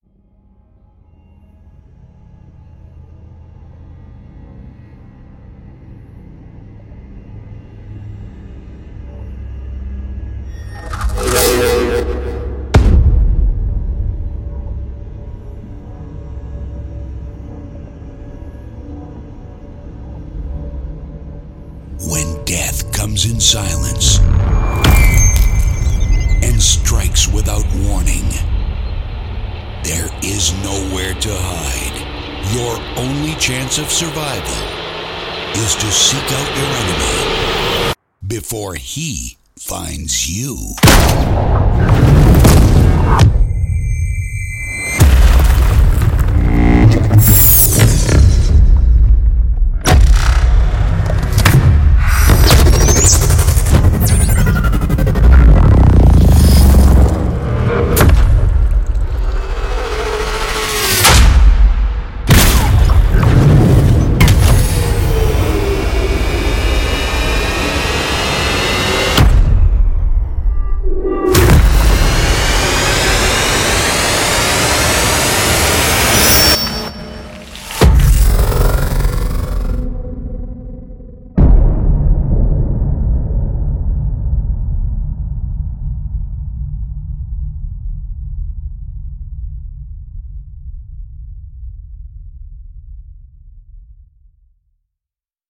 这个非凡的库包含各种启发性和灵活的元素，并为您带来了高质量的大片预告片风格的SFX，并且都可以使用-您可以将它们注入到现有项目中，以填补或混合缺少的部分与其他元素实现完全独特和不同的声音。